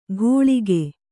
♪ ghōḷige